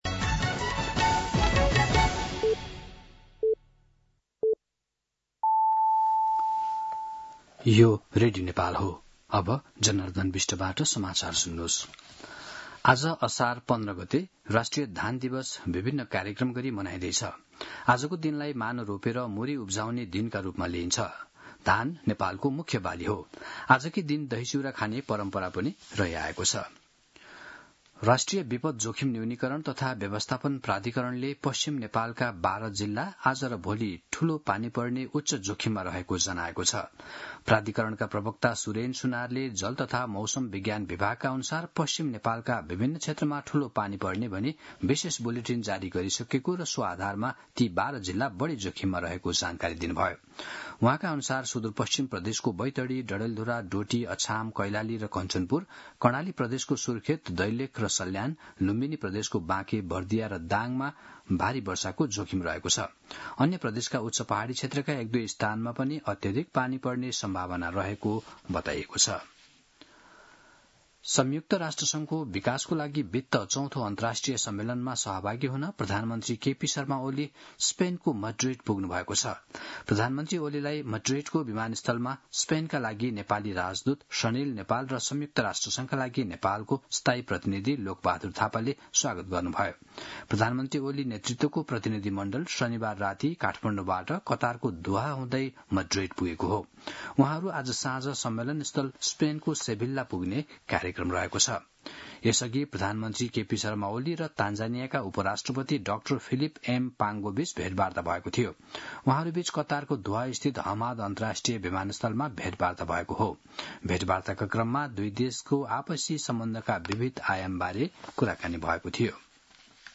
An online outlet of Nepal's national radio broadcaster
मध्यान्ह १२ बजेको नेपाली समाचार : १५ असार , २०८२